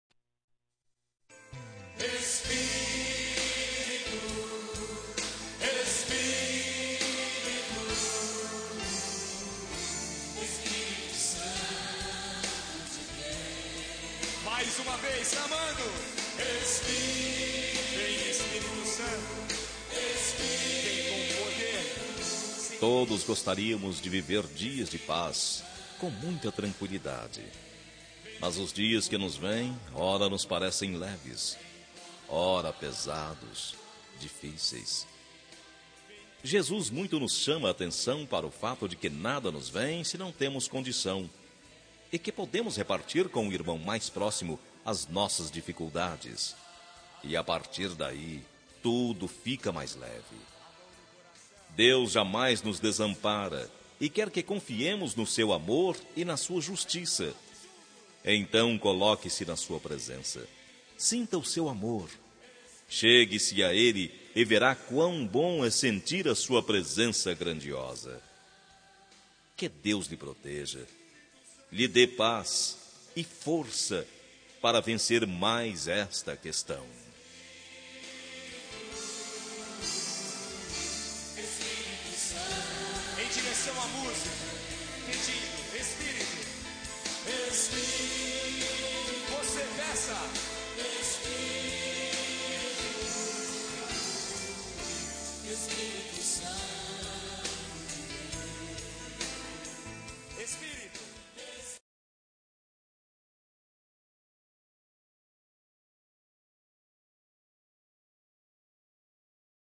Telemensagem de Otimismo – Voz Masculina – Cód: 4990-3 – Religiosa